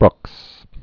(brŭks)